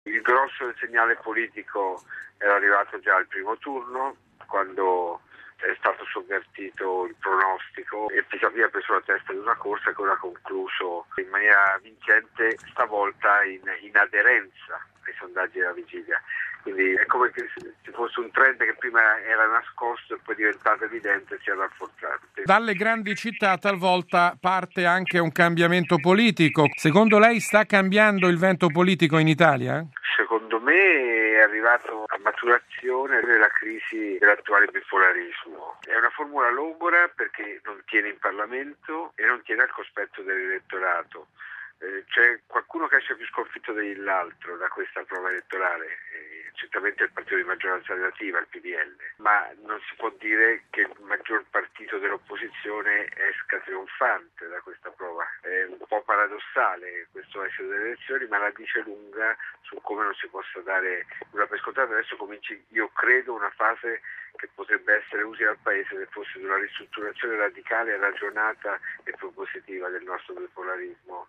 Per un'analisi globale del voto di ieri